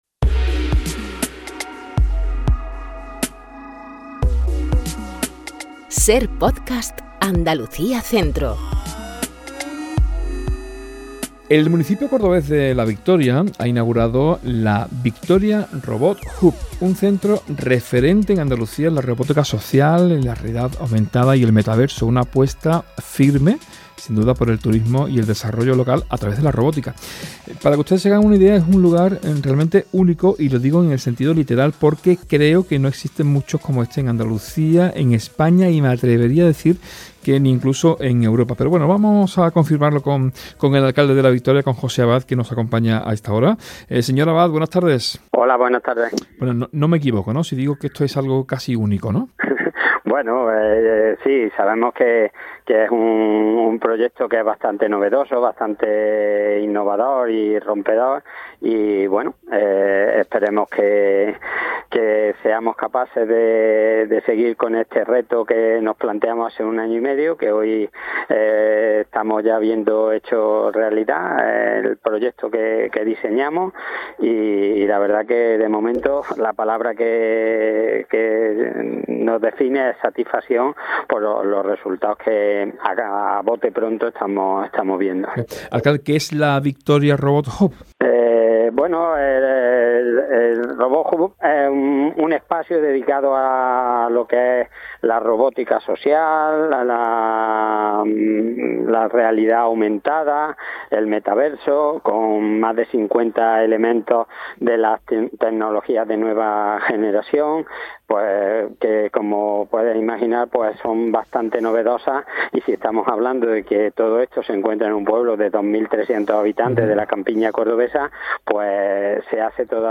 ENTREVISTA | José Abad, alcalde de La Victoria